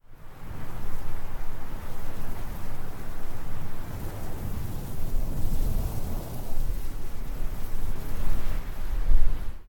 ComboWind.ogg